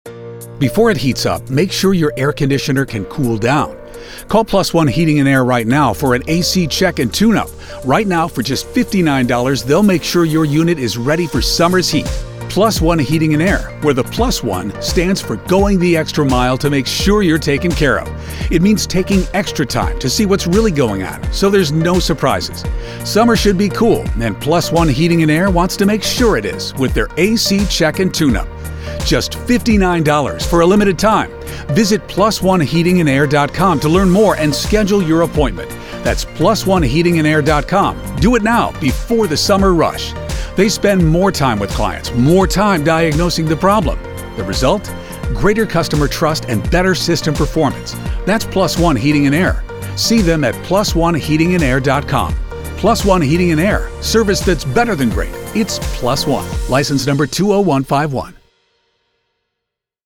Our current commercials: